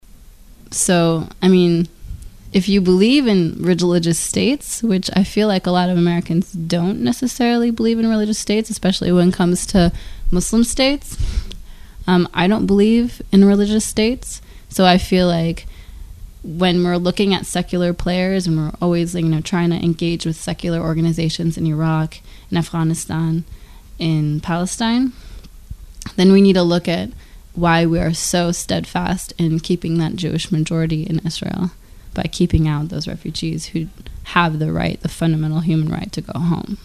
Palestine Interview